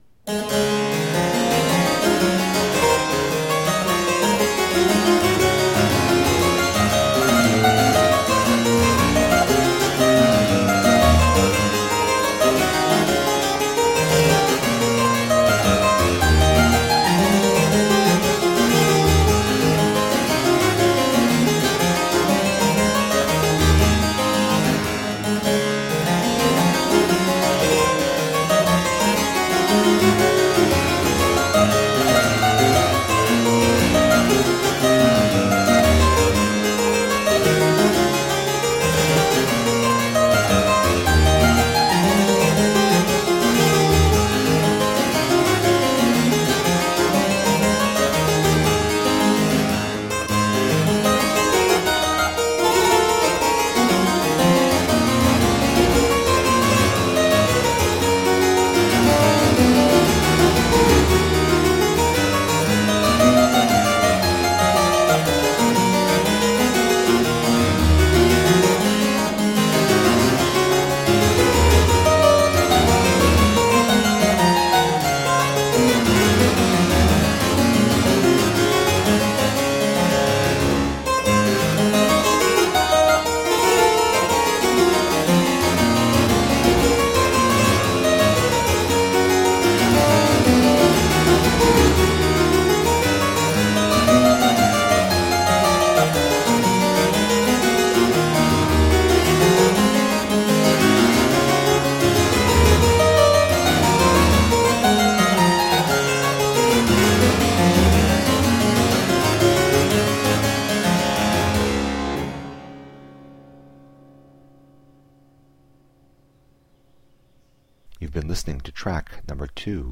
Bach on the harpsichord - poetic and expressive.